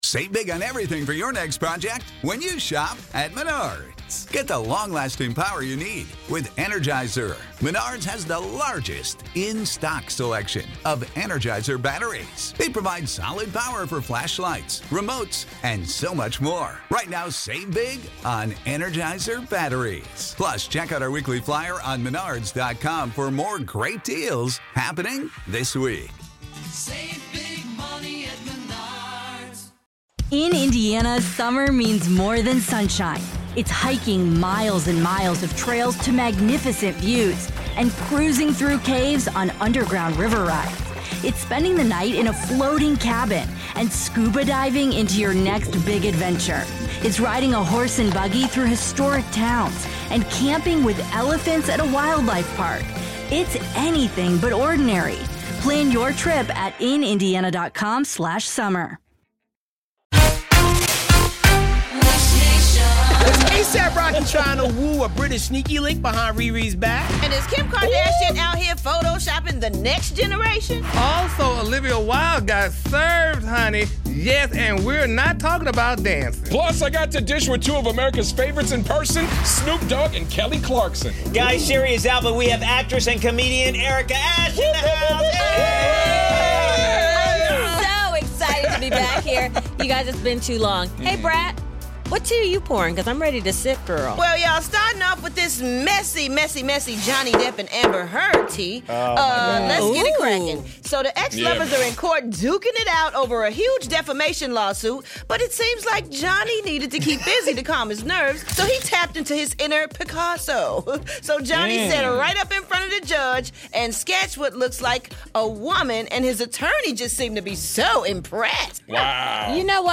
Erica Ash is in the studio co-hosting with us, so tune in to today's Dish Nation for some HOT celebrity dish!